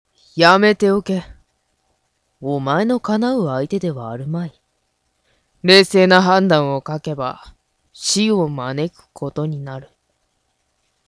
Sample　Voice
男声
温和：｢…そんなにカリカリしないでよ｣/ 青年・低め /